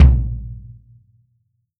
Index of /90_sSampleCDs/Best Service ProSamples vol.43 - Real Drum Kits [AIFF, EXS24, HALion, WAV] 1CD/PS-43 WAV REAL DRUMS/SWING KIT